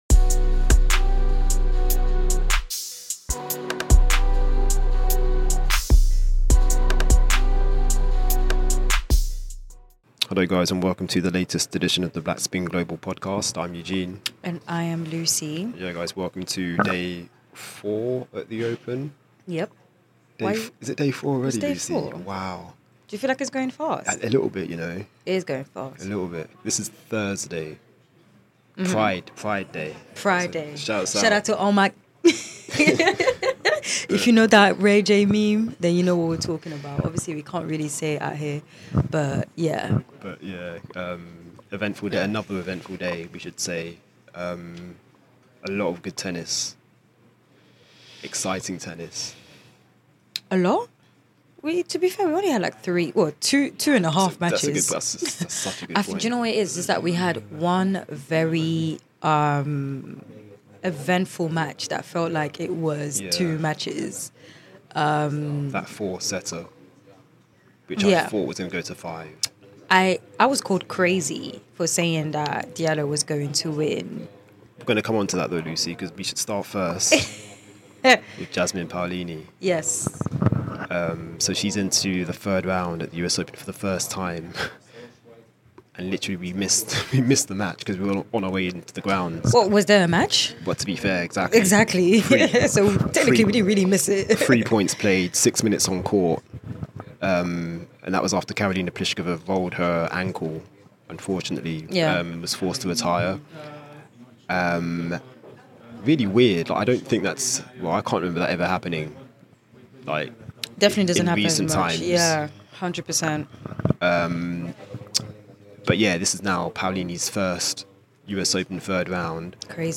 Listen out for interview snippets from Paolini, Diallo and Osaka.